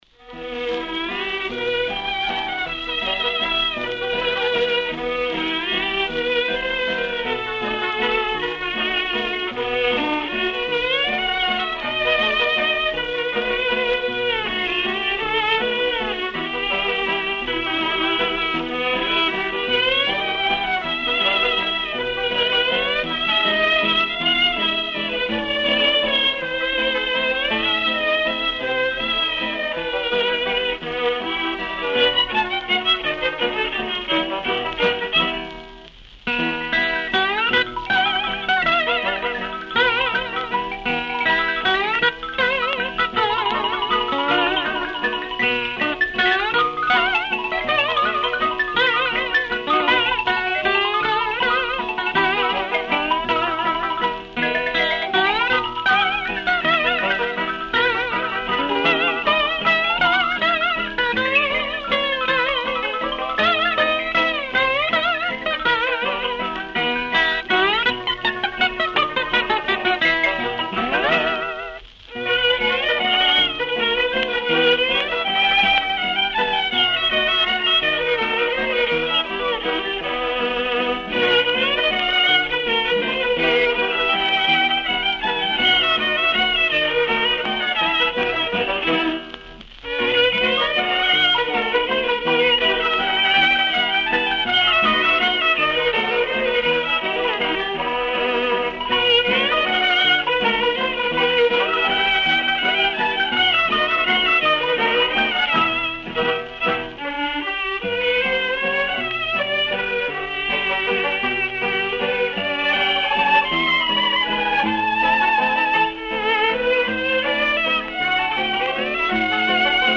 Каталожная категория: Танцевальный оркестр |
Жанр: Вальс
Вид аккомпанемента: Оркестр
Место записи: Нью-Йорк |